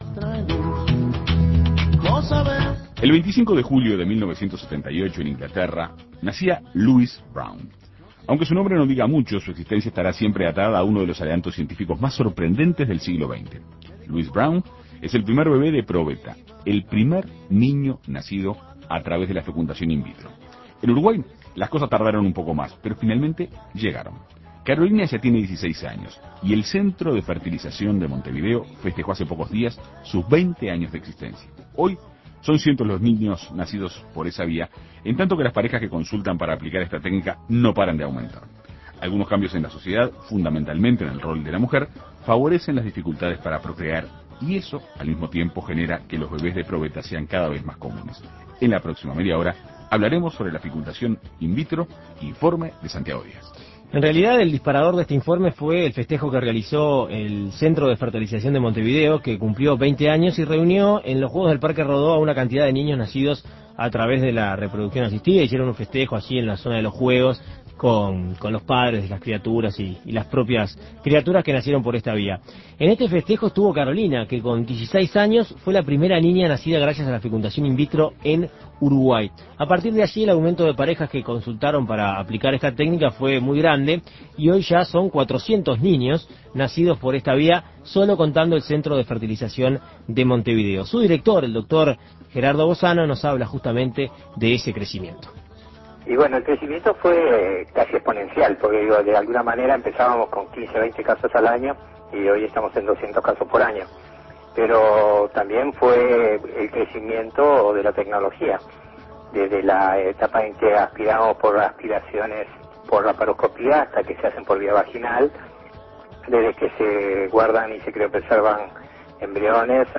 Informes La realidad de la fecundación in vitro en Uruguay Imprimir A- A A+ El 25 de julio de 1978 nació en Inglaterra Louise Brown, el primer bebé nacido a través de la fecundación in vitro.